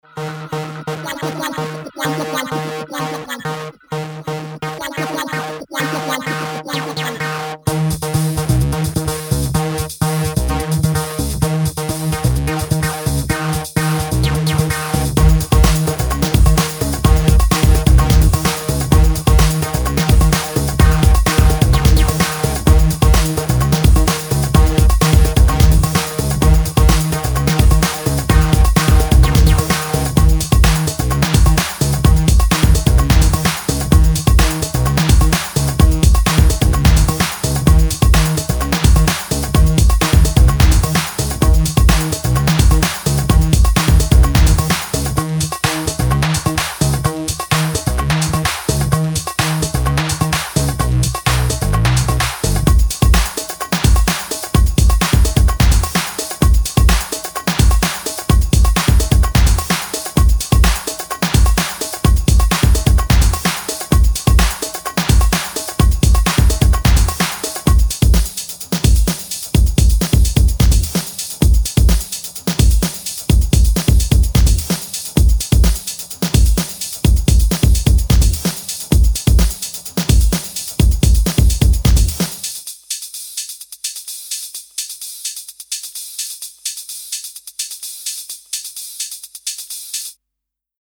極彩色で陶酔的なピークタイム・チューンを展開。